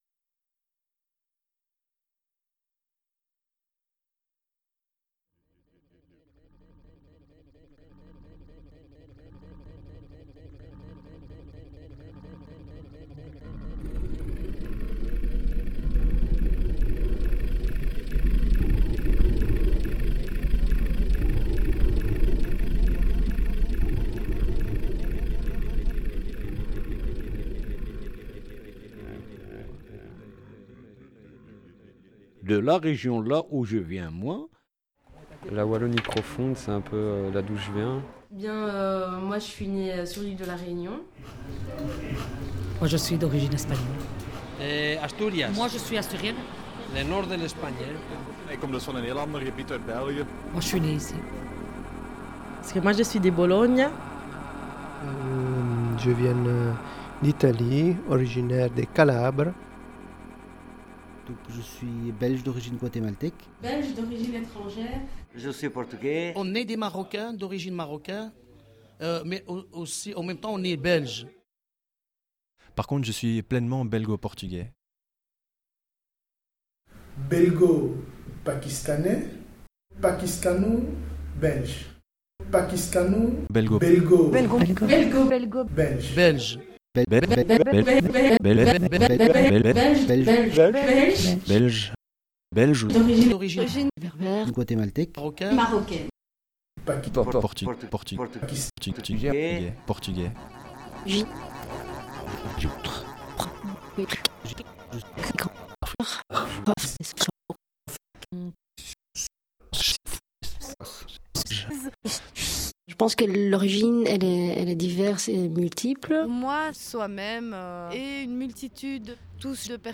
A sound-collage with material from the database of Parlez-vous Saint-Gilllois?.
Drifting between warm and affectionately spoken denominators for friends and family to ethnic generalisations, sometimes using the same words but in different intonations this remix crosses voices from the depths of the Brussels ‘low Saint-Gilles’ neighborhood ‘Bosnia’.